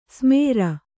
♪ smēra